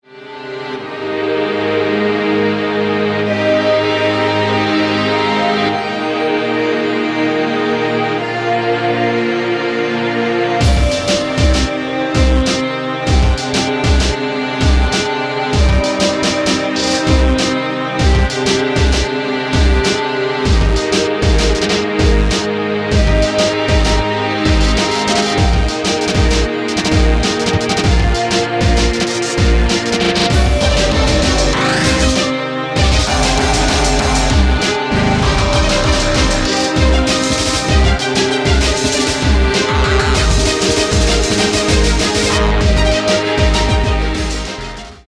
independent electronic music scene from Holland